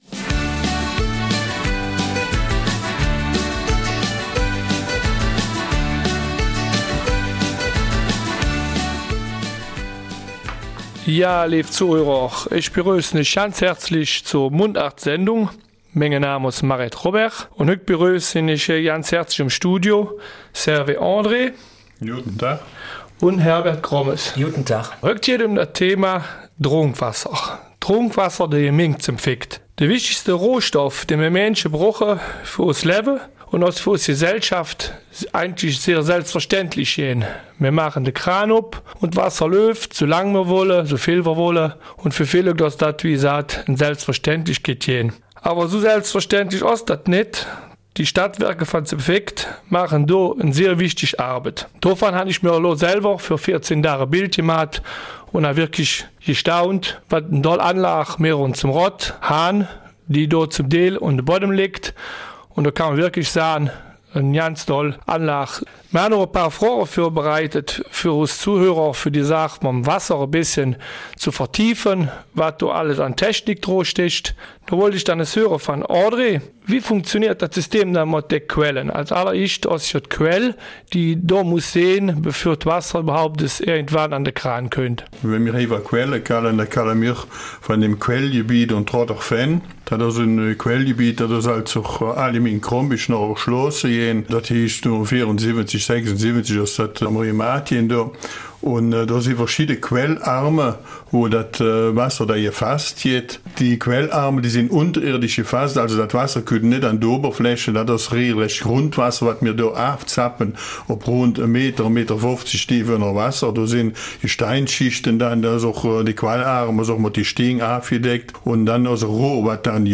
Eifeler Mundart: Trinkwasserversorgung in der Gemeinde St. Vith